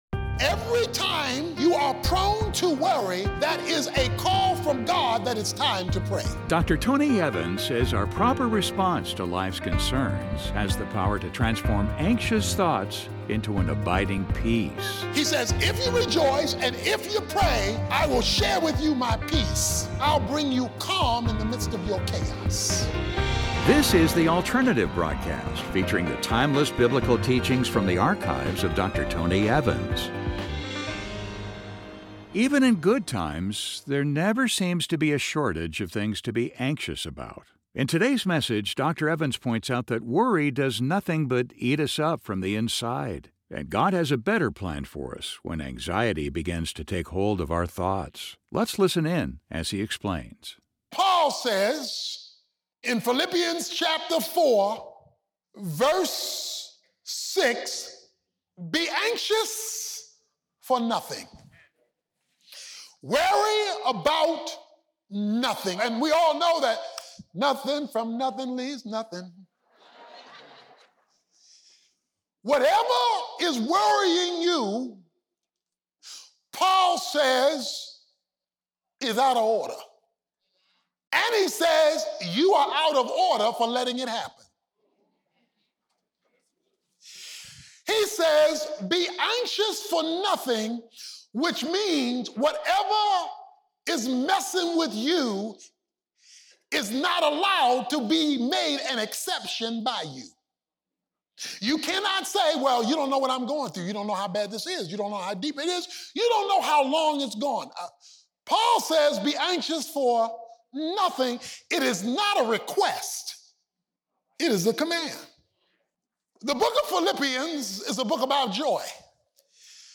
In this message, Dr. Tony Evans points out that worry does nothing but eat us up from the inside. God has a better plan for us when anxiety begins to take hold of our thoughts.